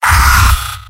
Giant Robot lines from MvM. This is an audio clip from the game Team Fortress 2 .
Demoman_mvm_m_painsharp07.mp3